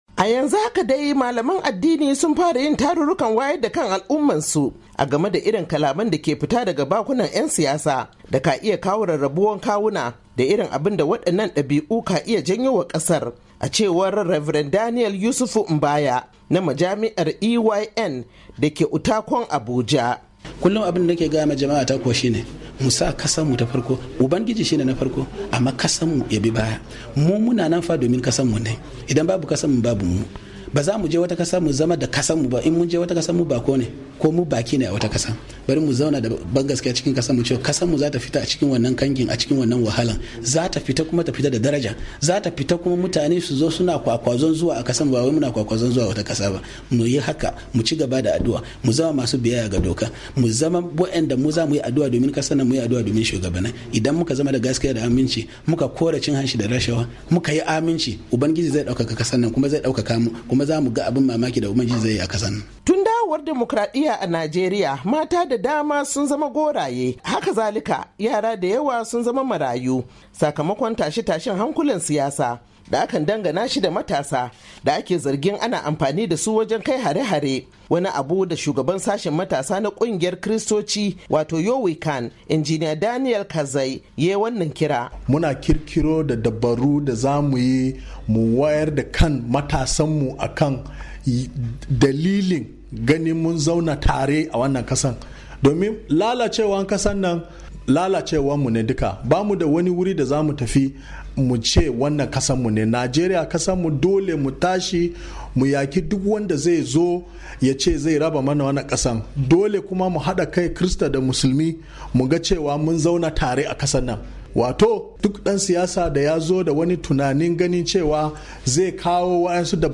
Sun yi wadannan kiraye-kirayen ne a wani taron bukin Sabon Shekarar Miladiyya a Abuja.